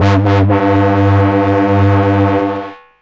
Bass x-tra 3.114.wav